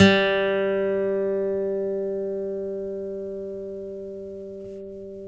Index of /90_sSampleCDs/Roland L-CD701/GTR_Steel String/GTR_18 String
GTR 6STR G07.wav